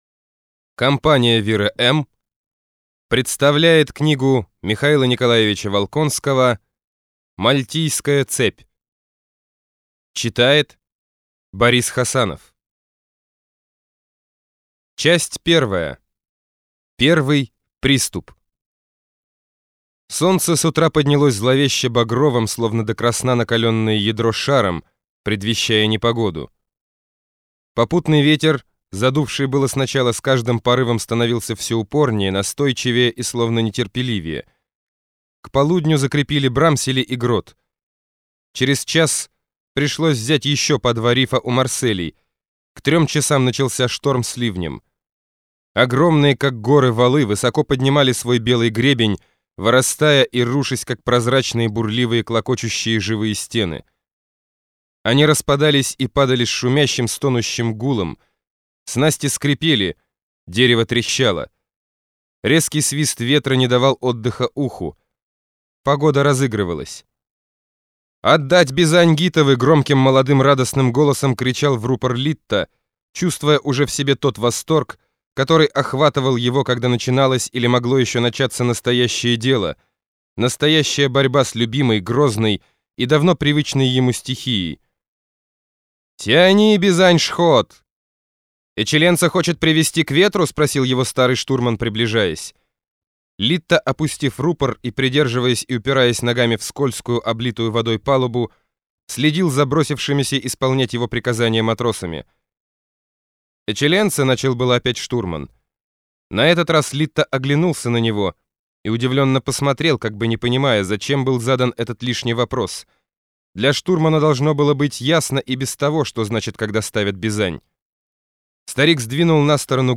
Аудиокнига Мальтийская цепь | Библиотека аудиокниг